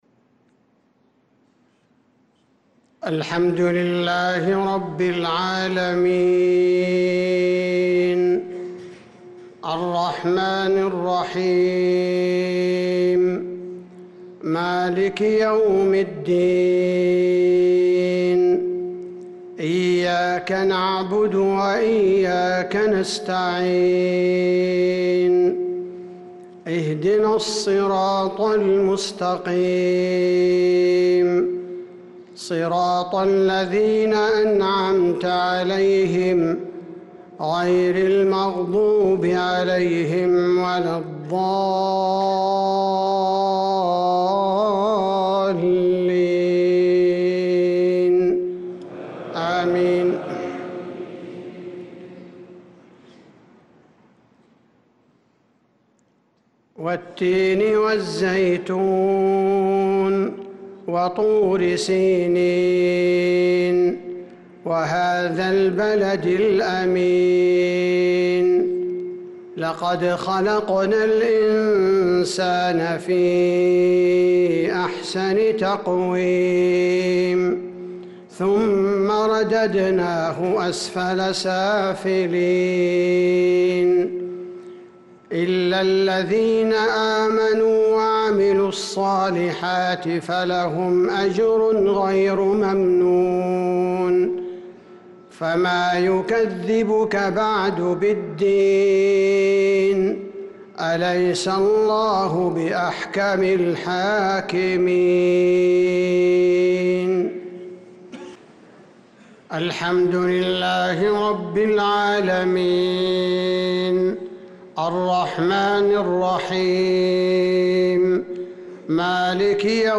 صلاة المغرب للقارئ عبدالباري الثبيتي 22 شوال 1445 هـ
تِلَاوَات الْحَرَمَيْن .